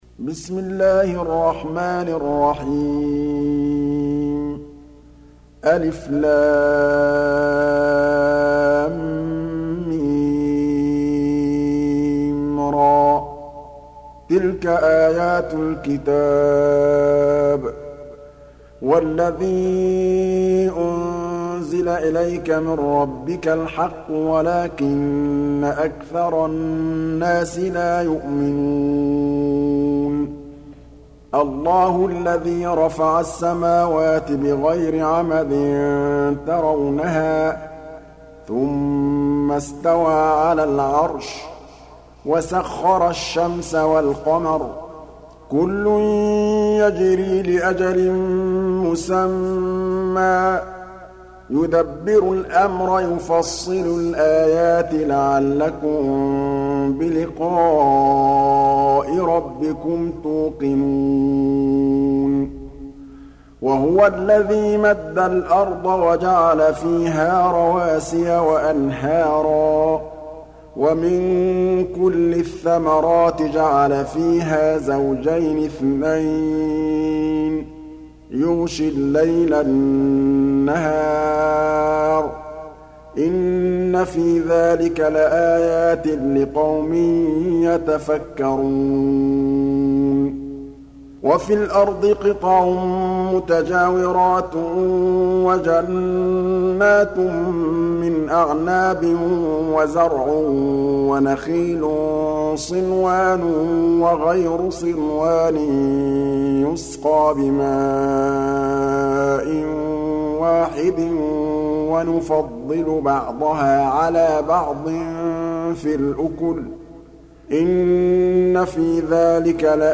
Surah Repeating تكرار السورة Download Surah حمّل السورة Reciting Murattalah Audio for 13. Surah Ar-Ra'd سورة الرعد N.B *Surah Includes Al-Basmalah Reciters Sequents تتابع التلاوات Reciters Repeats تكرار التلاوات